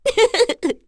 Cecilia-Vox_Happy1_kr.wav